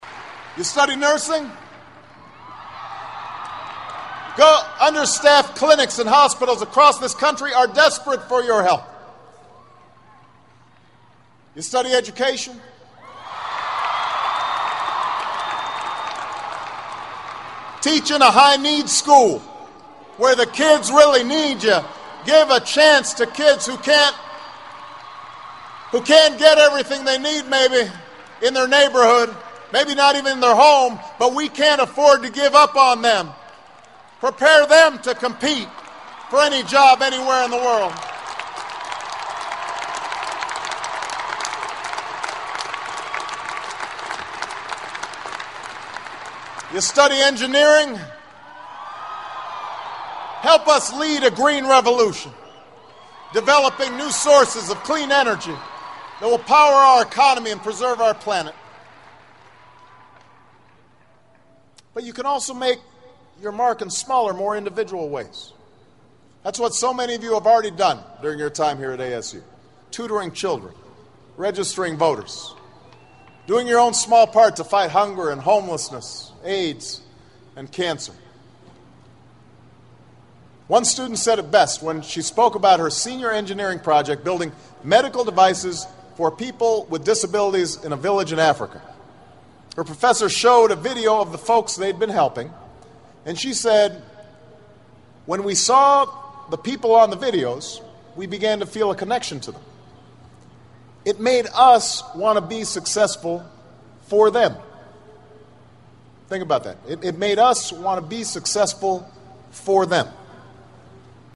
名校励志英语演讲 156:跨越物质的藩篱 让世界今非昔比(13) 听力文件下载—在线英语听力室
借音频听演讲，感受现场的气氛，聆听名人之声，感悟世界级人物送给大学毕业生的成功忠告。